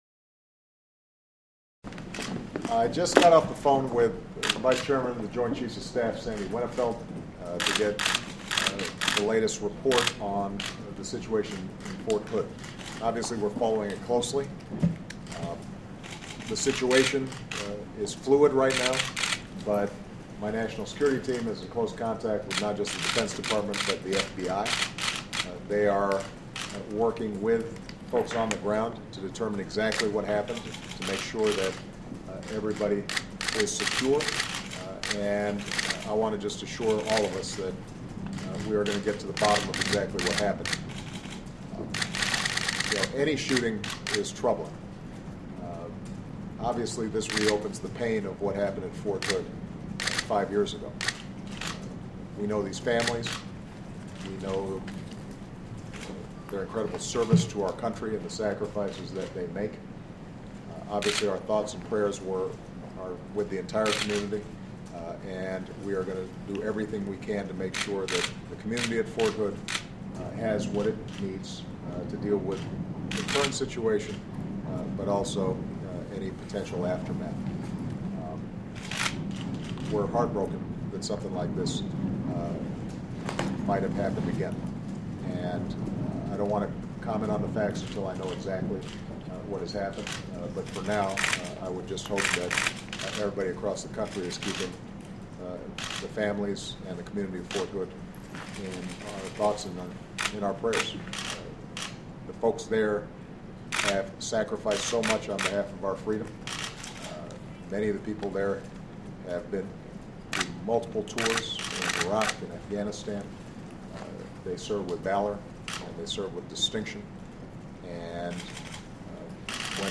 U.S. President Barack Obama makes a statement about the shooting at the Fort Hood, Texas military base which took place earlier in the day